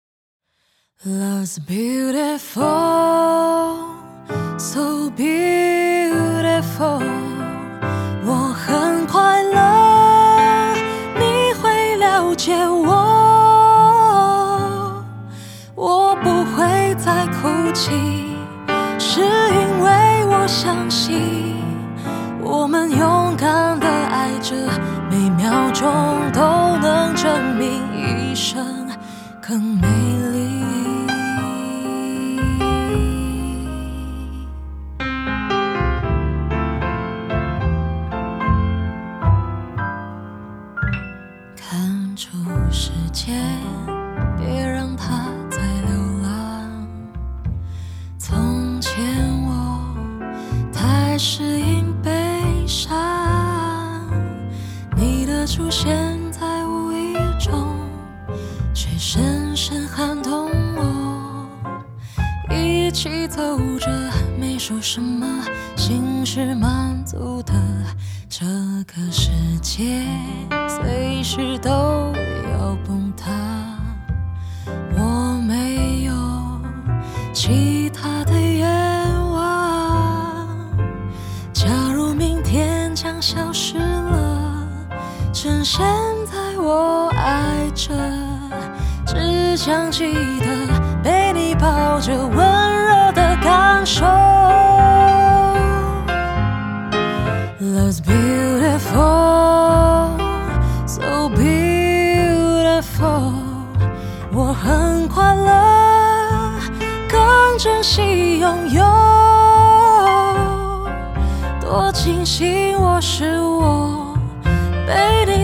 ★ 晶瑩剔透的嗓音，如訴說故事般溫婉飄逸的美聲！
★ 披頭四御用艾比路錄音室精心打造，原音極致重現！
當然，這張唱片也延續一貫的發燒風格，絕美的人聲、寬廣的動態、細膩的樂器，都讓發燒友享受美好歌曲、也享受發燒音質！